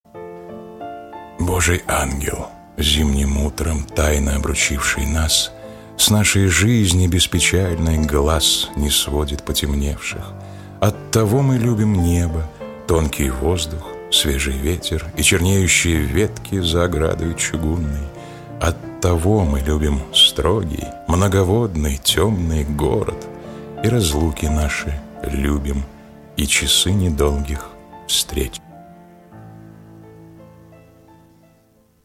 1. «А. А. Ахматова – Божий Ангел, зимним утром… (читает Николай Мартон)» /